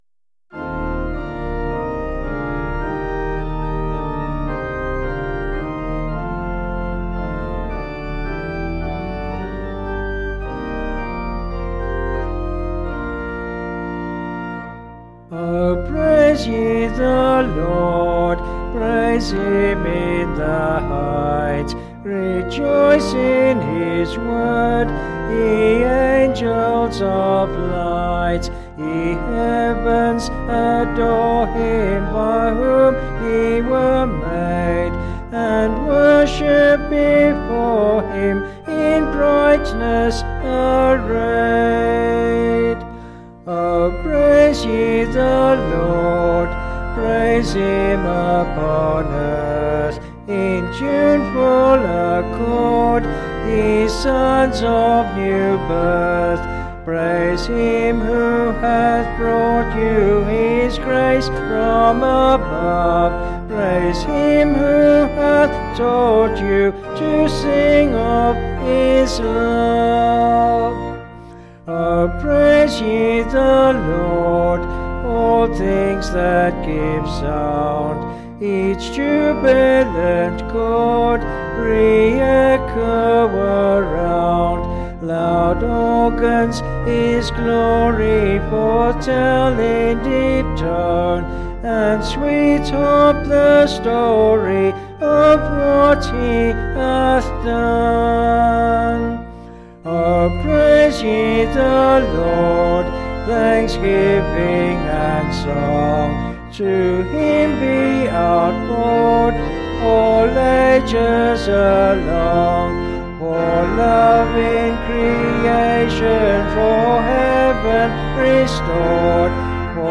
Vocals and Organ   263.1kb Sung Lyrics